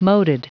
Prononciation du mot moated en anglais (fichier audio)
Prononciation du mot : moated